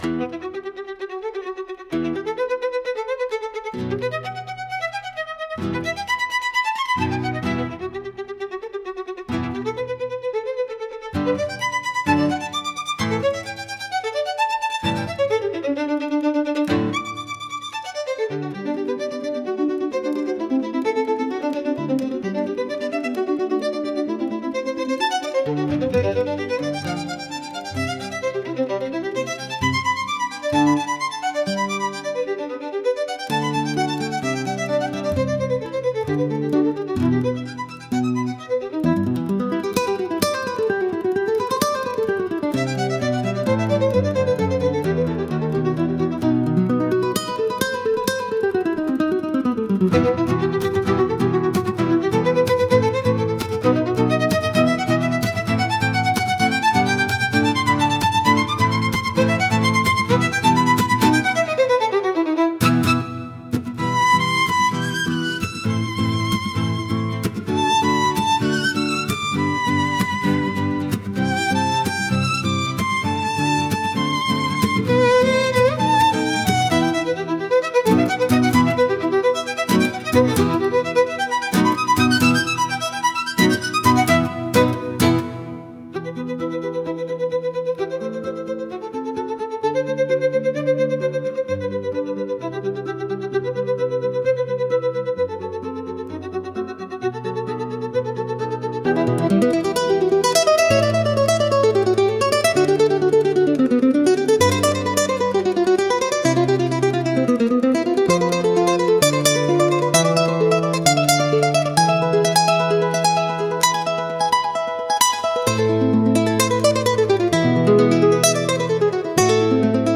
Instrumental / 歌なし